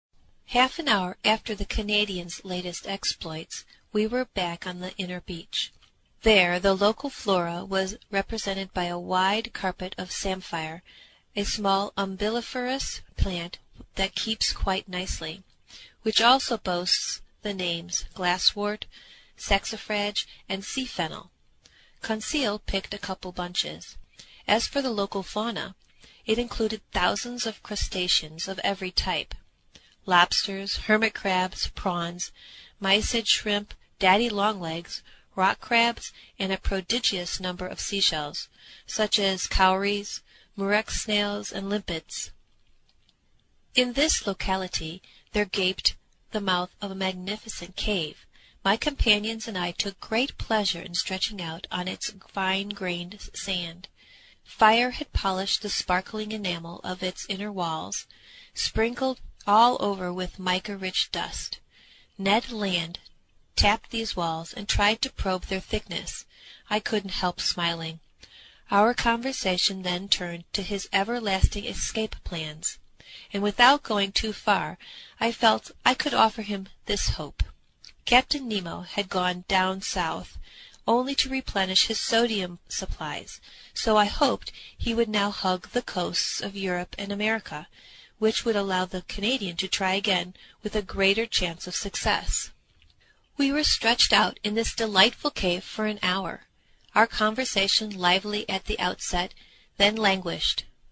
英语听书《海底两万里》第401期 第25章 地中海四十八小时(32) 听力文件下载—在线英语听力室
在线英语听力室英语听书《海底两万里》第401期 第25章 地中海四十八小时(32)的听力文件下载,《海底两万里》中英双语有声读物附MP3下载